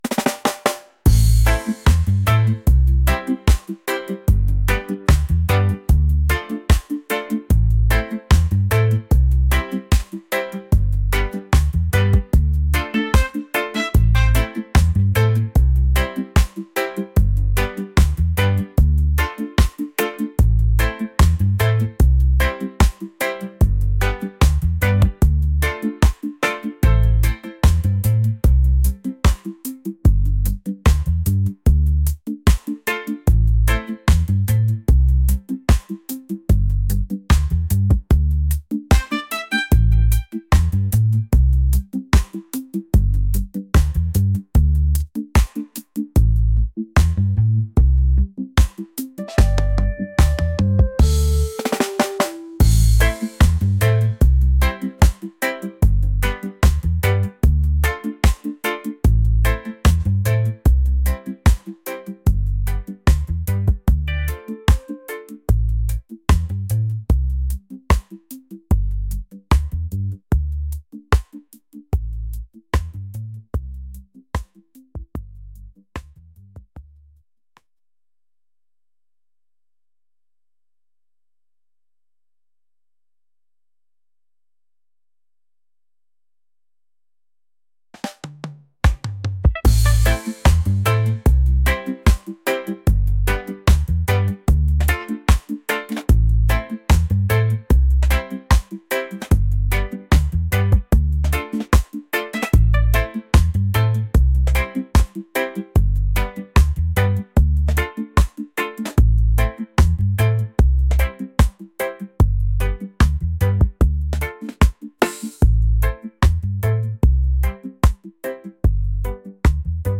groovy | laid-back | reggae